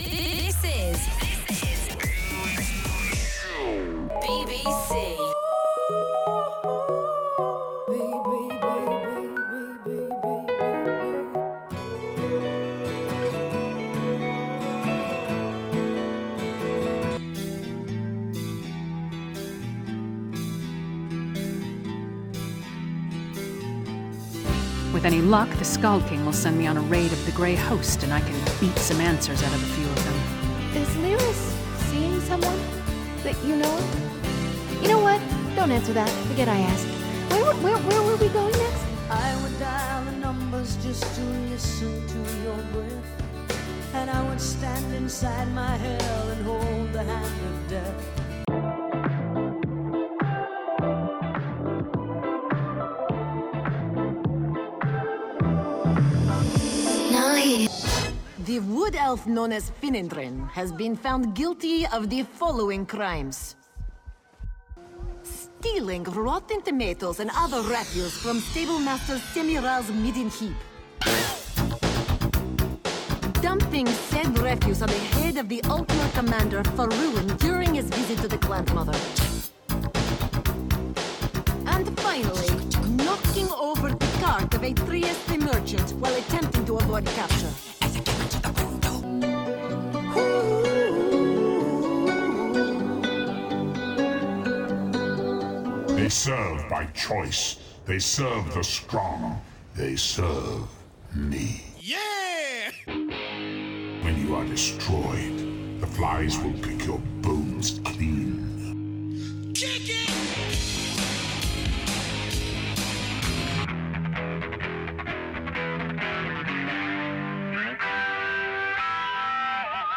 Here’s how to play the BBC Jukebox from Oblivion Contest: Listen to the audio file (below), identify as many of the 12 song snippets as you can and enter your guesses on the offical entry form .